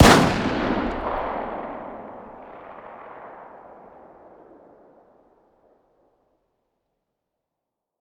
fire-dist-357sig-pistol-ext-01.ogg